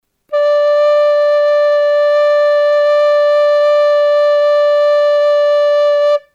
A nota RE.
Tapa todos os buratos da túa frauta excepto último burato da man dereita segundo este esquema.
Nota Re